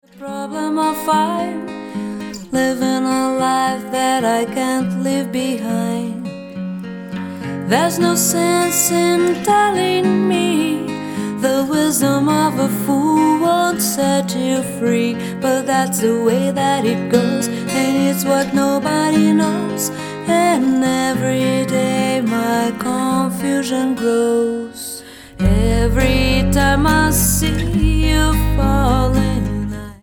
Genre: Vocal